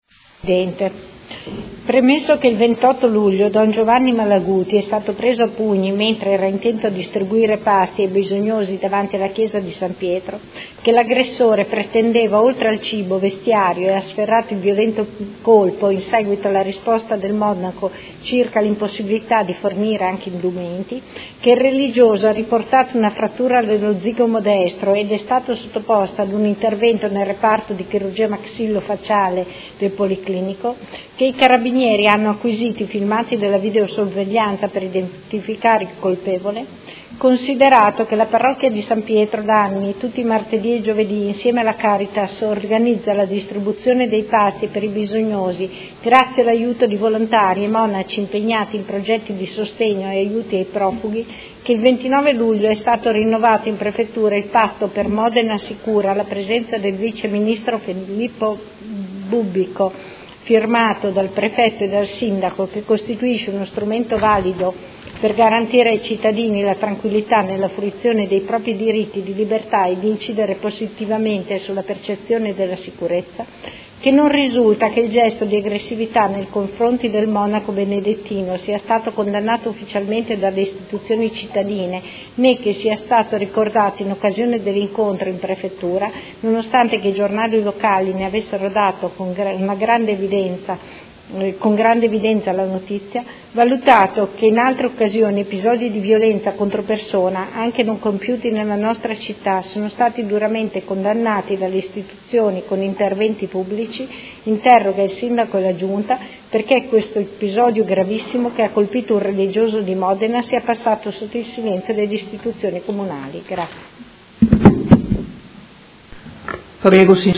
Seduta del 22/09/2016 Interrogazione della Consigliera Santoro (IDEA - Popolarti Liberali) avente per oggetto: Silenzio delle Istituzioni su un episodio grave di aggressione ad un monaco